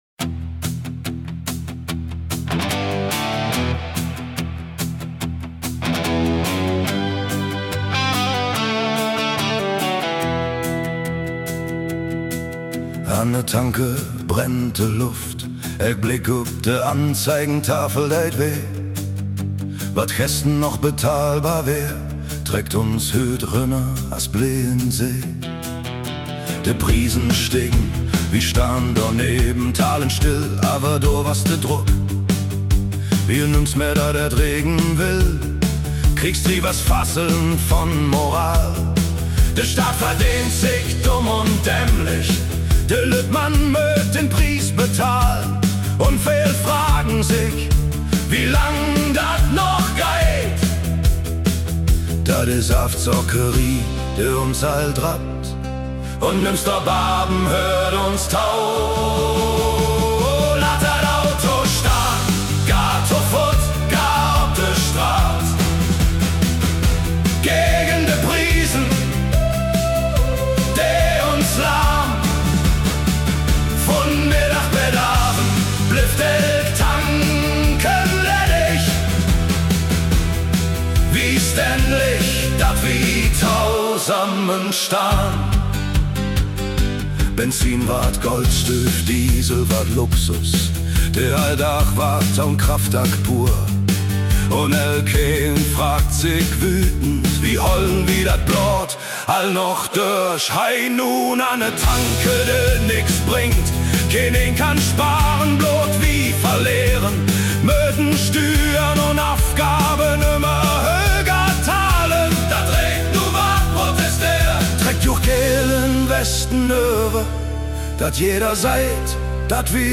protest_song_high_noon_an_der_tanke_plattdeutsch.mp3